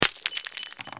razbitaya_chaska.ogg